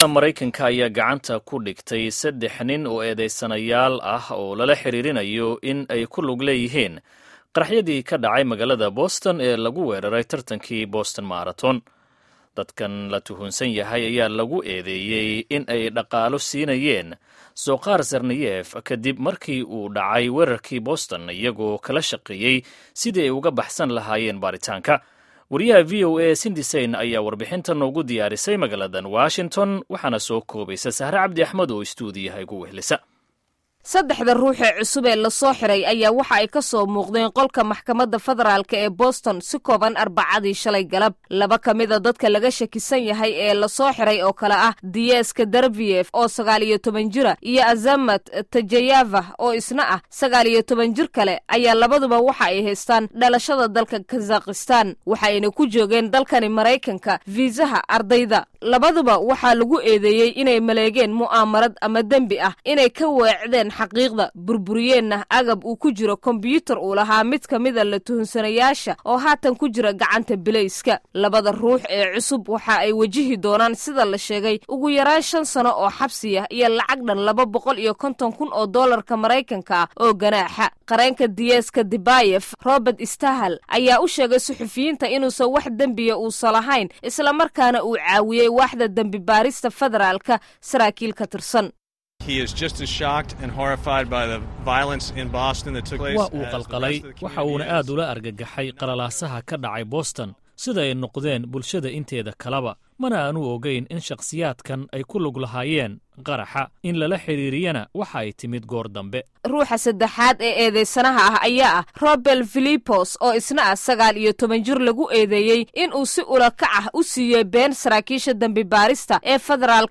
Warbixinta Ragga la Tuhmay